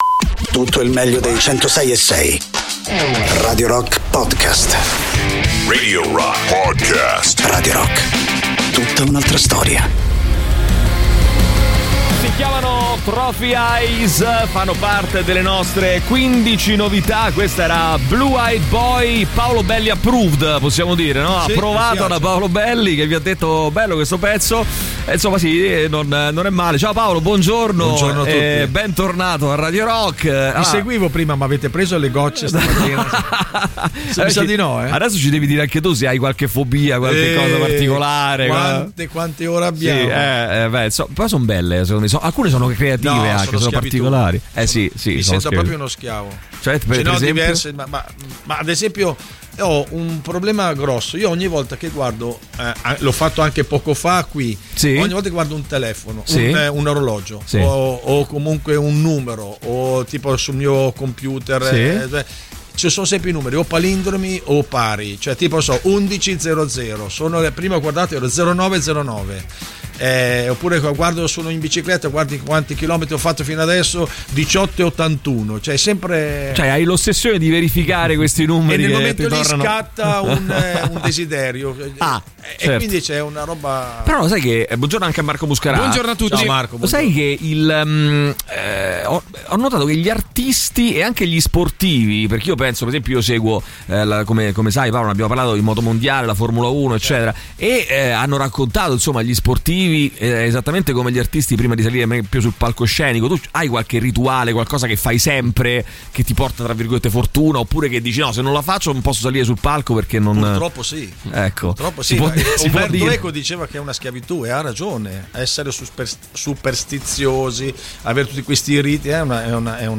Interviste: Paolo Belli (05-04-22)